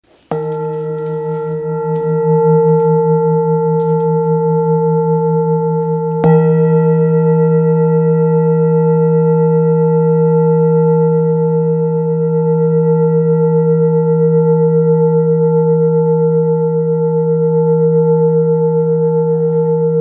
Jambati Singing Bowl-31162
Material Seven Bronze Metal
Jamabati bowl is a hand-beaten bowl.
It may be utilized in a recuperating treatment, including chakra equilibrium and sound washing. It can discharge an exceptionally low dependable tone.